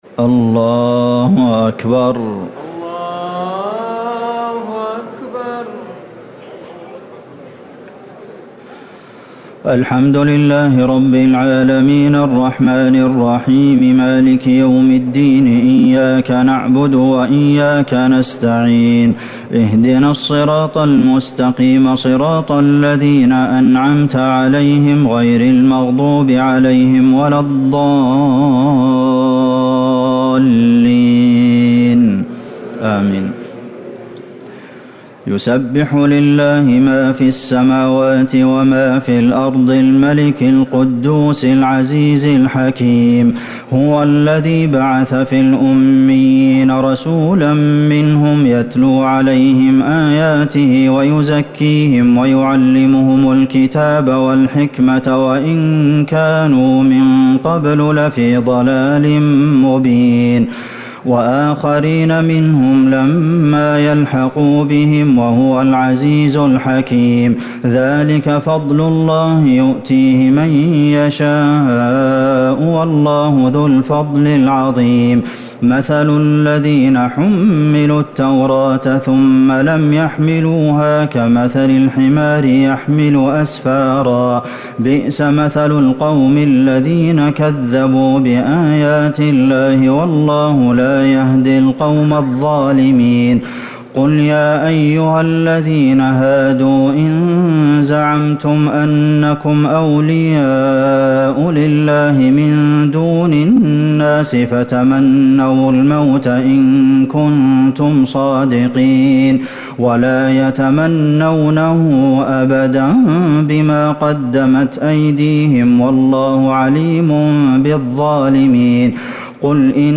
تراويح ٢٧ رمضان ١٤٤٠ من سورة الجمعة - التحريم > تراويح الحرم النبوي عام 1440 🕌 > التراويح - تلاوات الحرمين